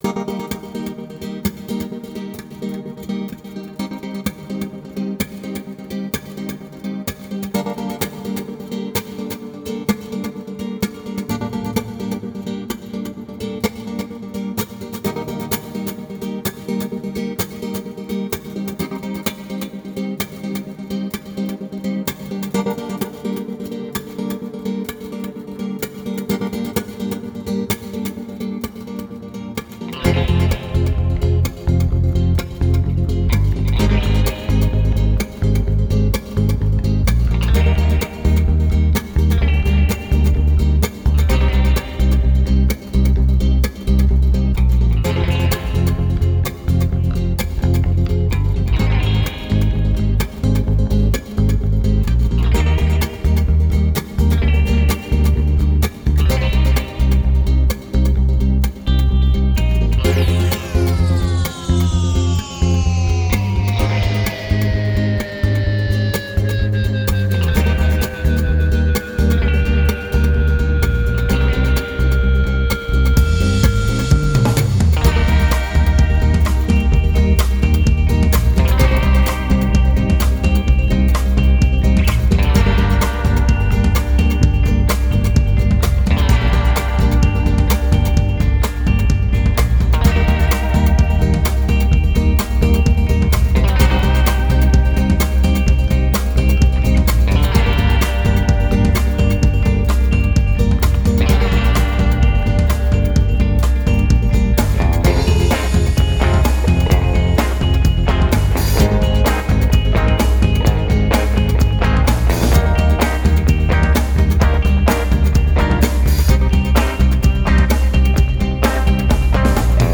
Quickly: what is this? Genre: singersongwriter.